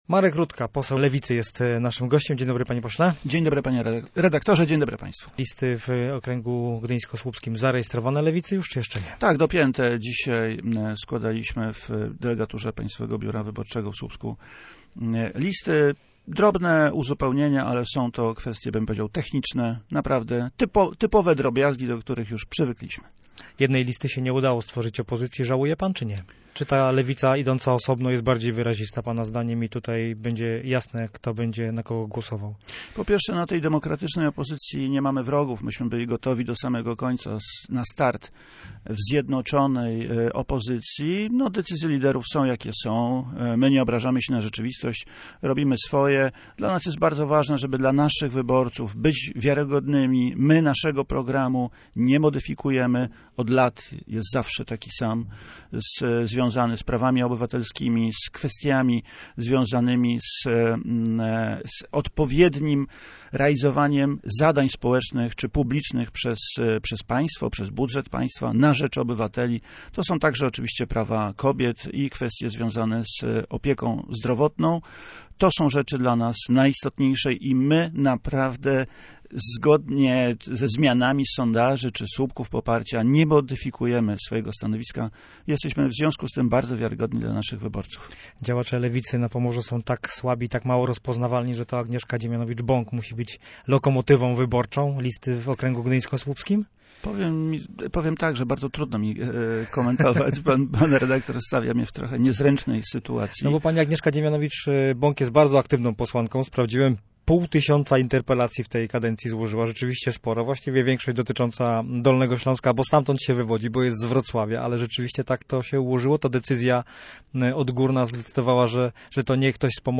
Marek Rutka był gościem miejskiego programu Radia Gdańsk Studio Słupsk 102 FM.